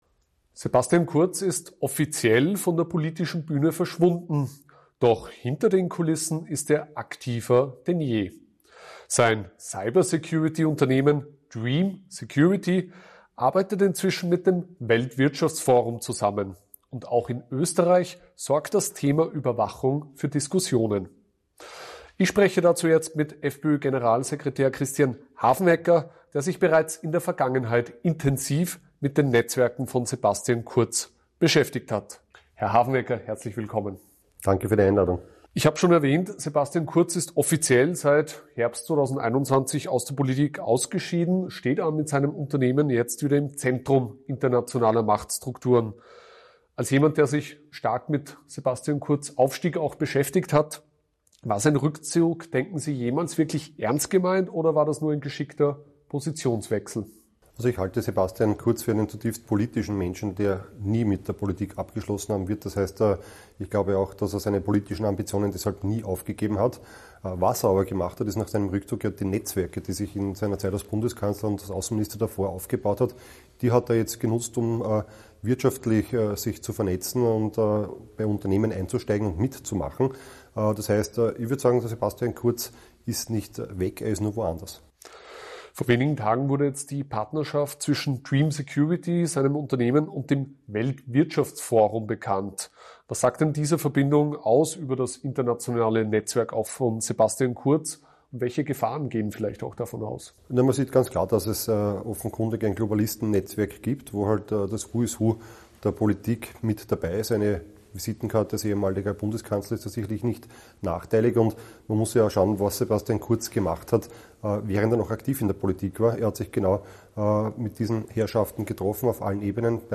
Im Interview mit AUF1 warnt FPÖ-Generalsekretär Christian Hafenecker vor den Netzwerken von Sebastian Kurz und spricht von gefährlichen Verbindungen zwischen dessen Cyber-Security-Unternehmen und dem WEF.